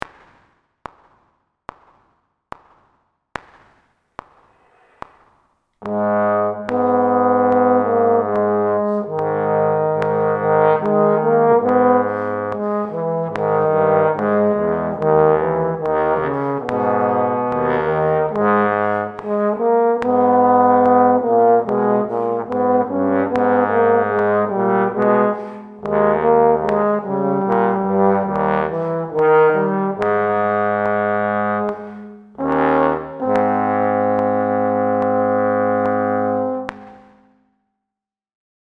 Here’s the audio of the (individual) harmony parts.
G-sharp minor
Min-08-g-sharp.mp3